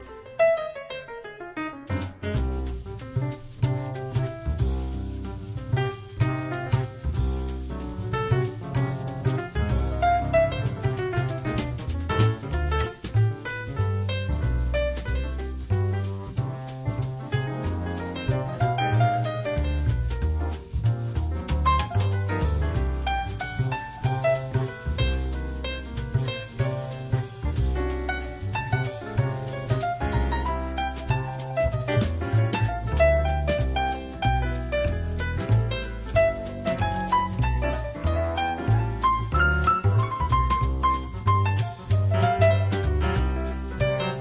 The Best In British Jazz
Recorded Eastcote Studios, West London 2006